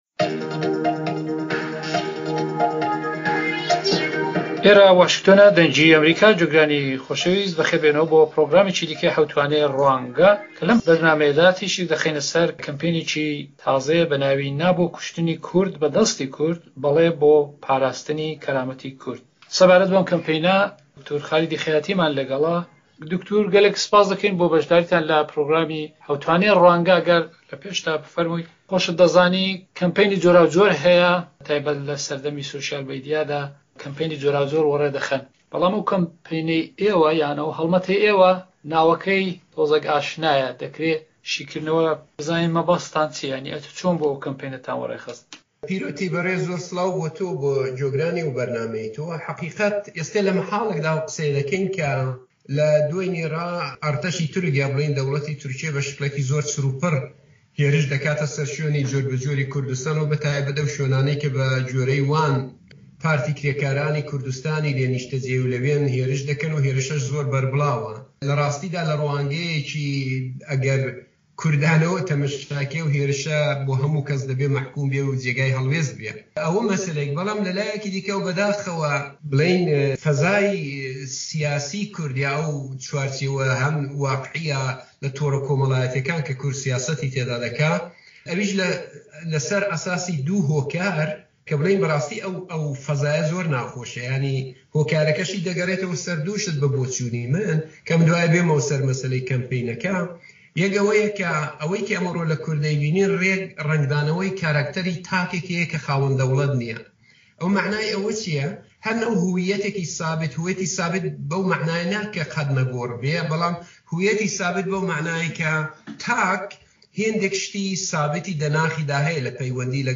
مێزگردی حەوتوانەی ڕوانگە